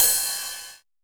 909 RIDE.wav